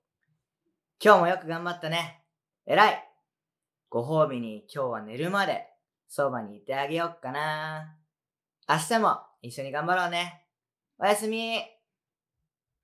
シューヤ おやすみボイス
シューヤおやすみボイス.wav